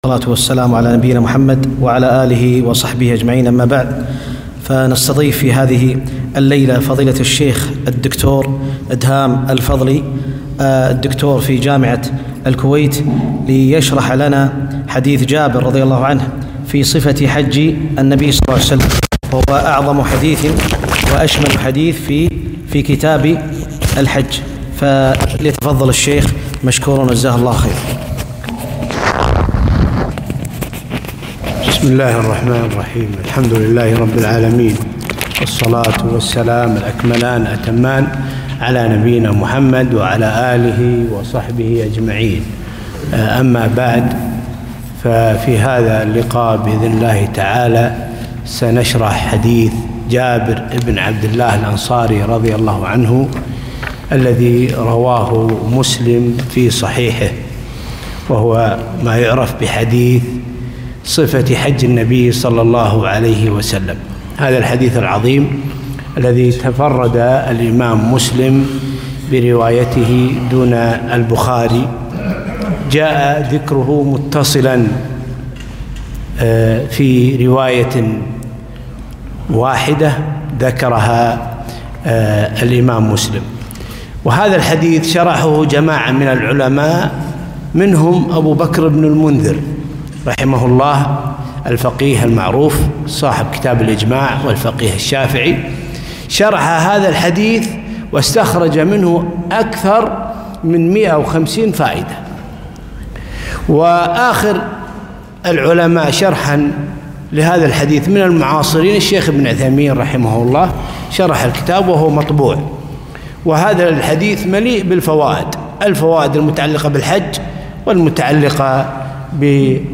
محاضرة - شرح حديث جابر بن عبدالله رضي الله عنهما - دروس الكويت